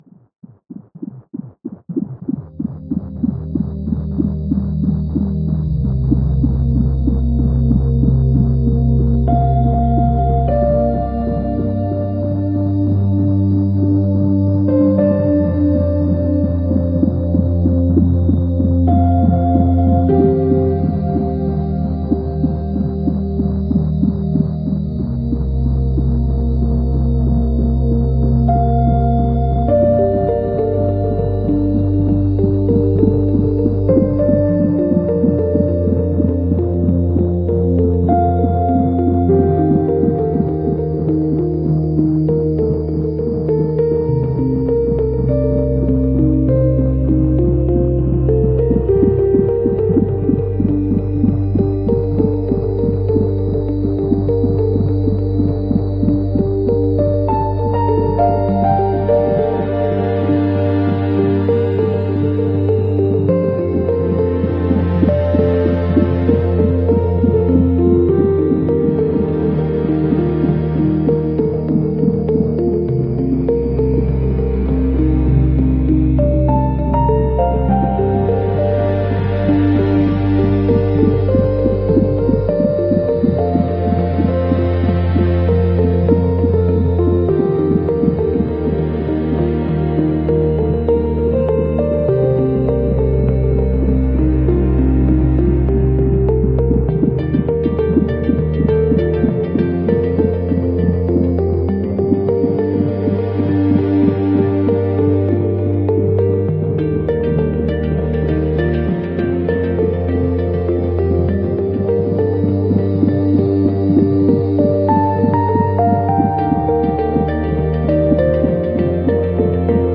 A musical masterpiece created from — real cardiac data.